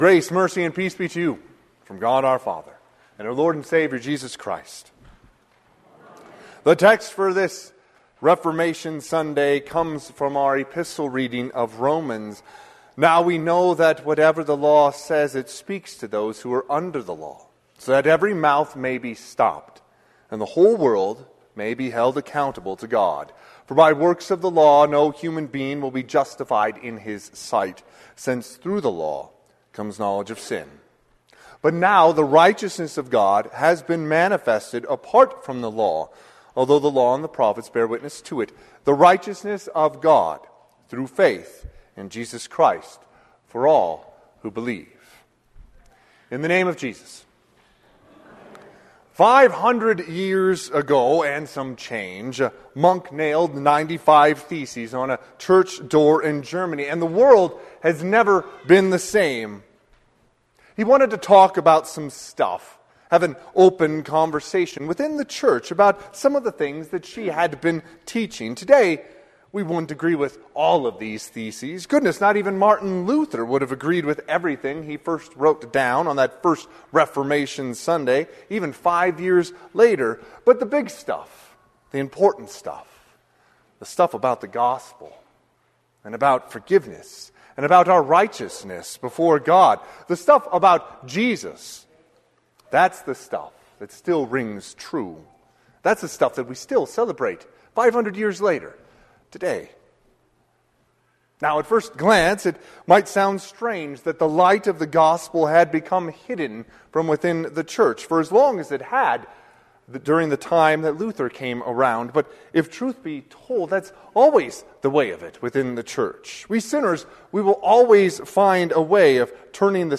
Sermon - 10/27/2024 - Wheat Ridge Evangelical Lutheran Church, Wheat Ridge, Colorado
Reformation Sunday